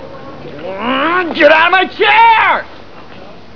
- Brad Pitt playing a lunatic in 12 Monkeys -